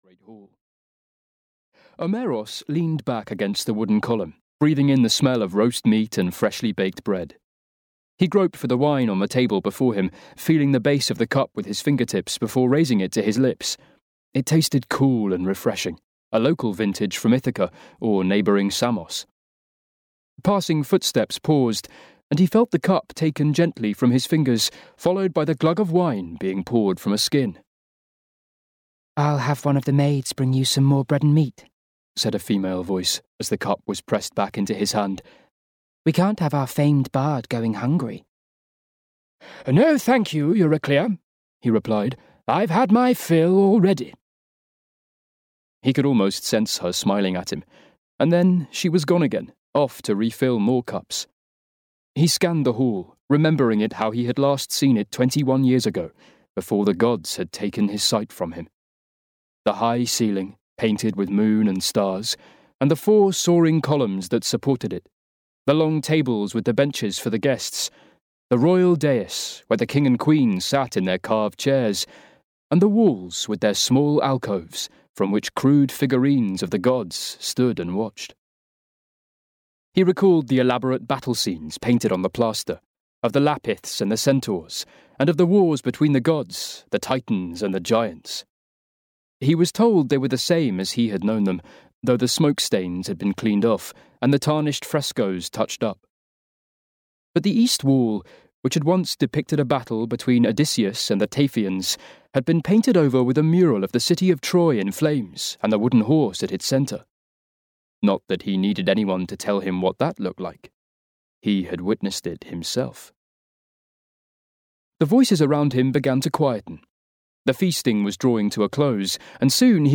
Son of Zeus (EN) audiokniha
Ukázka z knihy